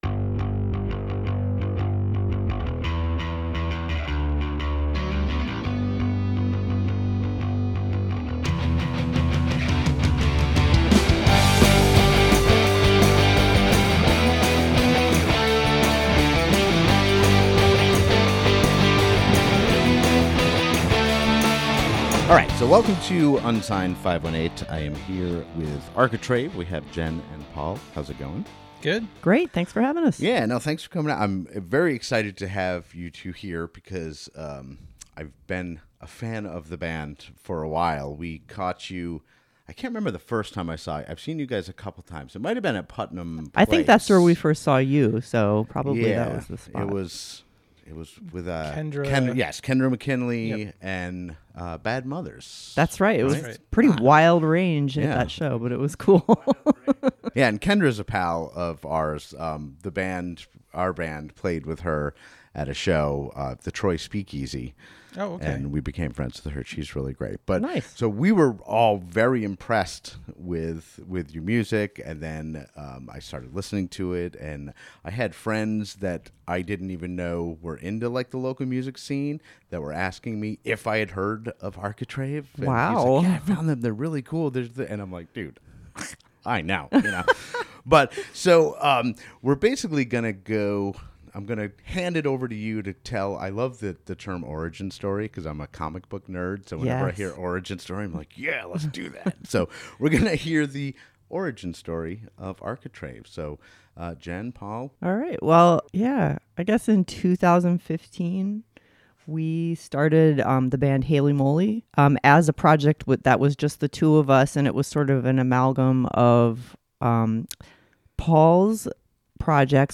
They came by the Unsigned518 studio (aka The Dazzle Den) and we discussed some of those other projects, as well as their amazing new video for Architrave's latest single "Lorem Ipsum".